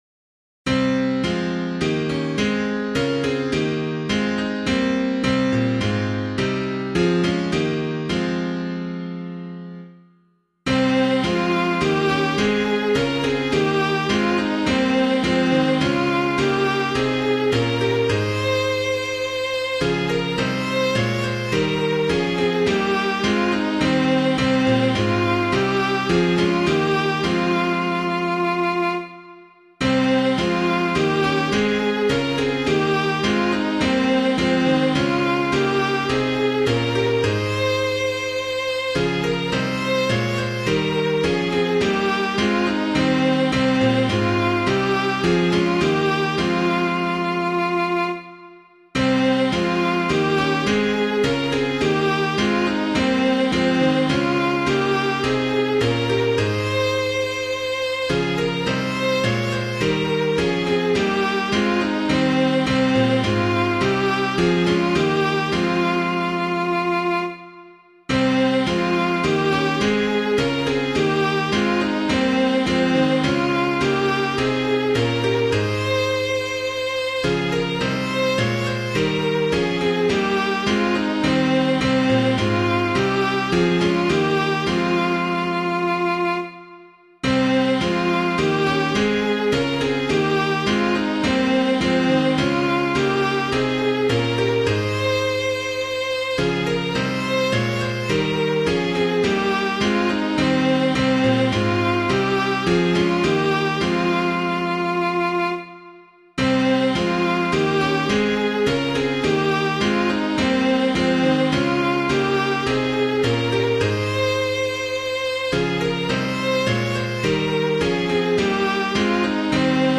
Hymn of the Day:  4th Sunday of Advent, Year C
piano